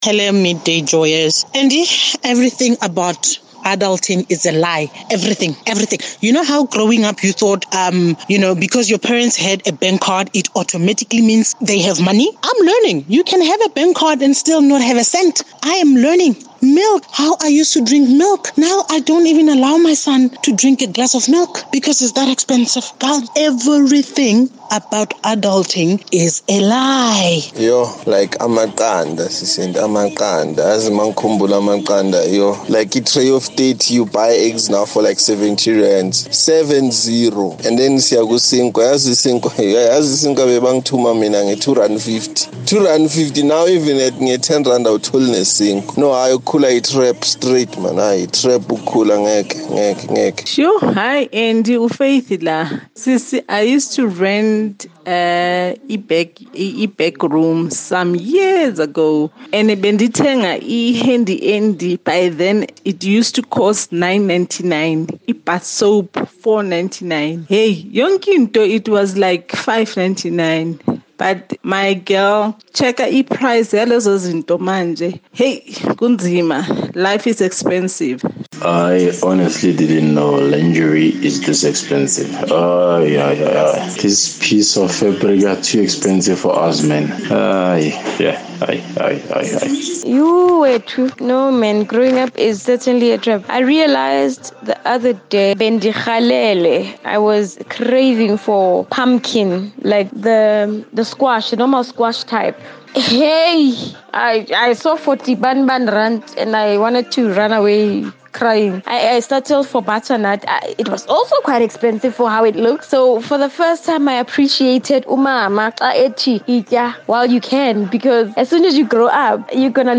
Listen to the conversation on Midday Joy: MONTAGE OF TOPIC